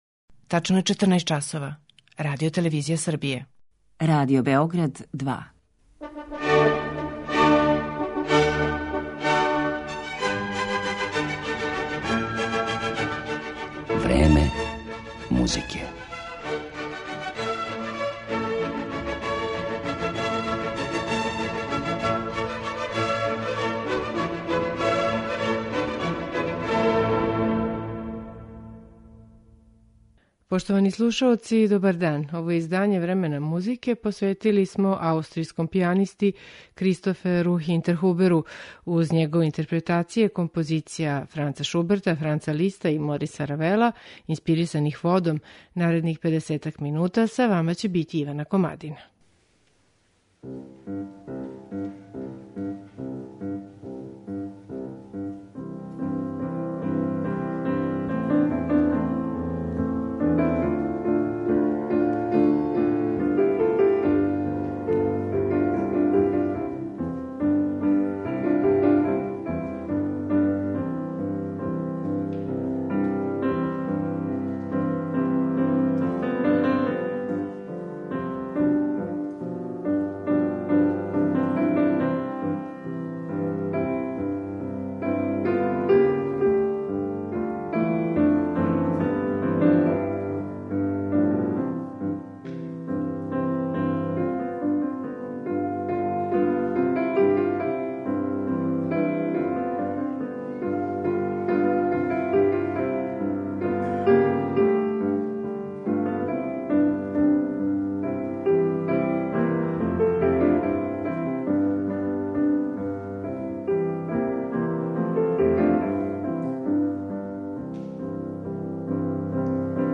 Представљамо вам аустриjског пијанисту